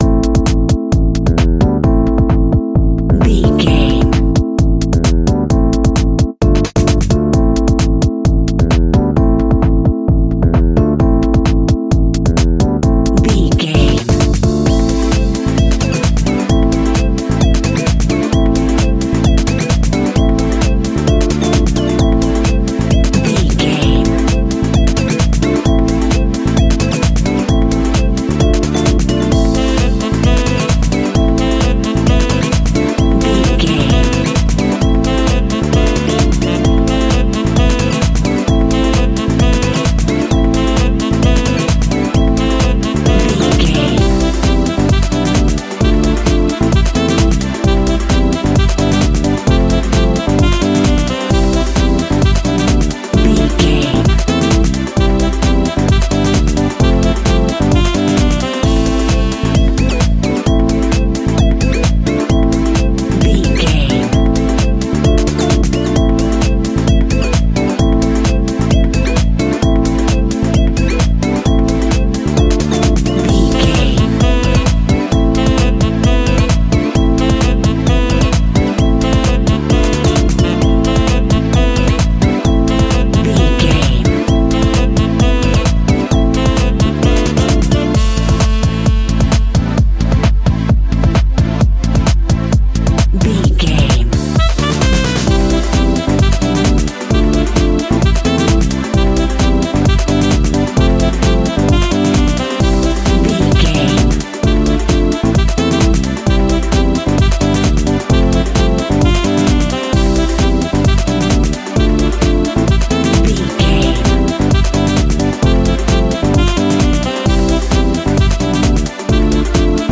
Funky House Music for the Island Disco.
Aeolian/Minor
fun
groovy
uplifting
driving
energetic
drum machine
synthesiser
electric piano
strings
saxophone
funky house
nu disco
upbeat
instrumentals